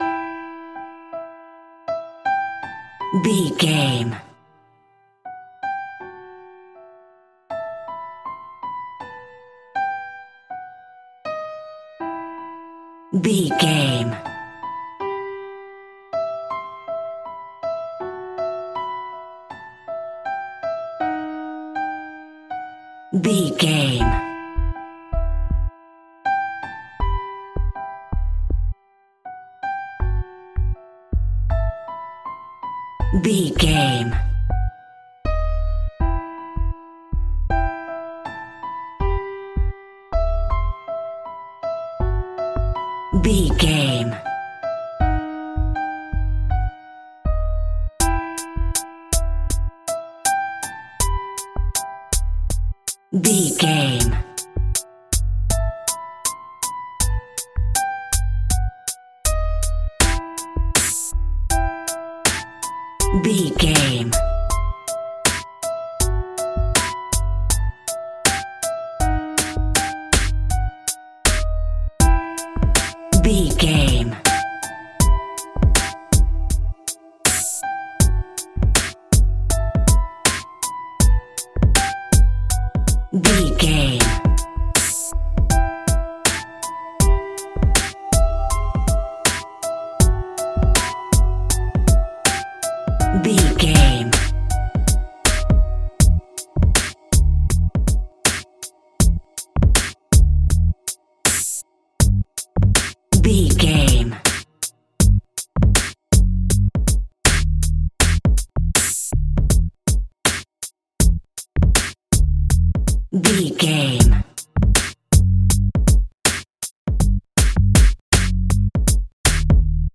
Aeolian/Minor
E♭
ominous
dark
haunting
eerie
piano
drum machine
synthesizer
Synth Pads
atmospheres